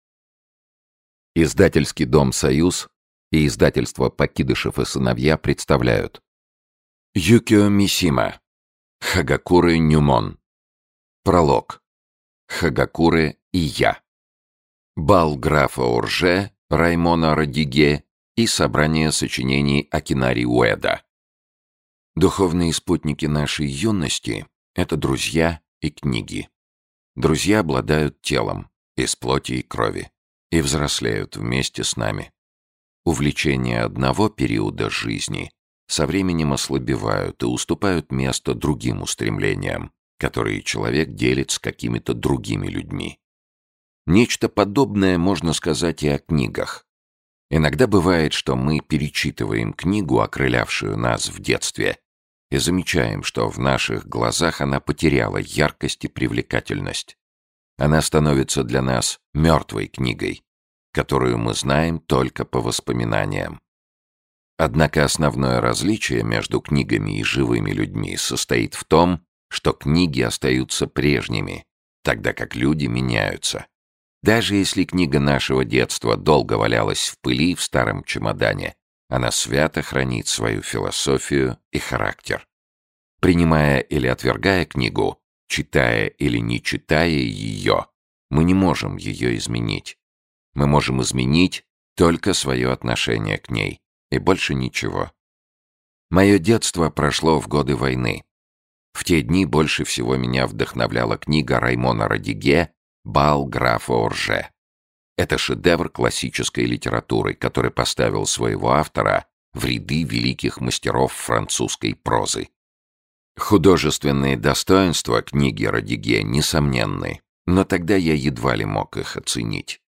Аудиокнига Хагакурэ Нюмон | Библиотека аудиокниг
Aудиокнига Хагакурэ Нюмон Автор Юкио Мисима Читает аудиокнигу Сергей Чонишвили.